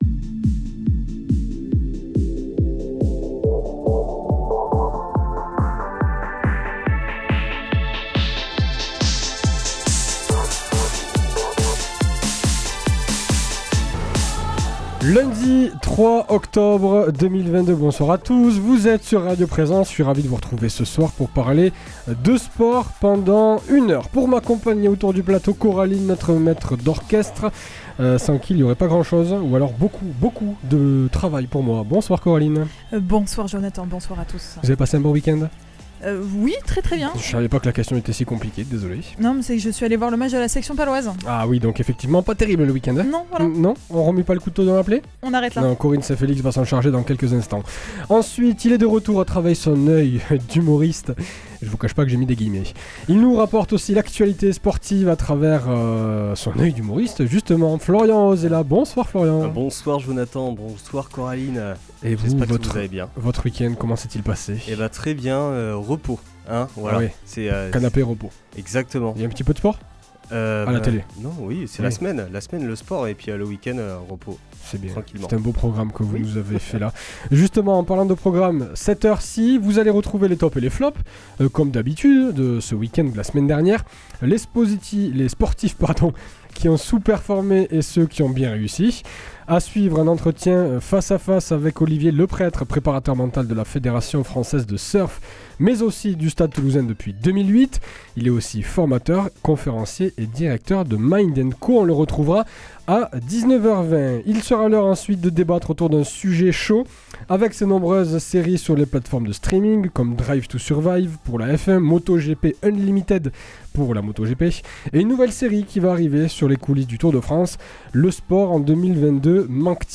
Et surtout un débat, avec ses nombreuses séries sur les plateformes de streaming (Drive to Survive pour la Formule 1, Moto GP Unlimited, et une nouvelle série sur le Tour de France), trouvez-vous que le sport en 2022 manque-t-il de saveurs ?